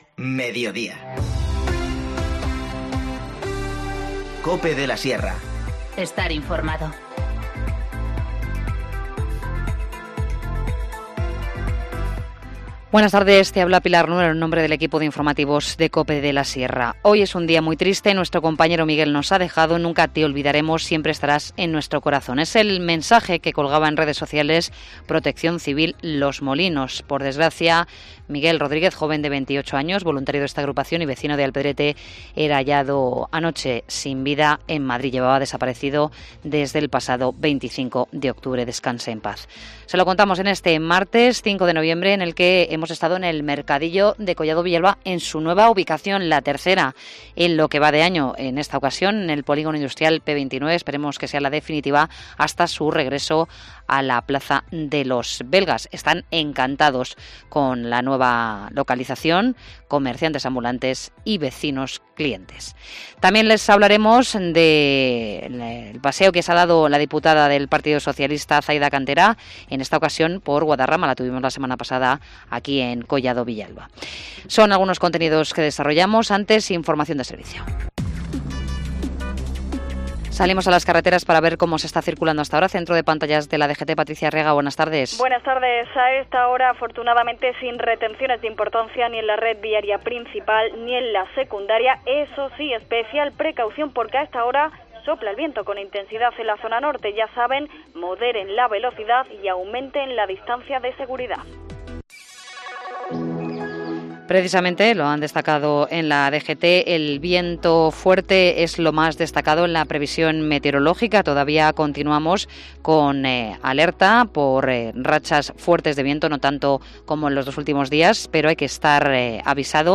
Informativo Mediodía 5 noviembre 14:20h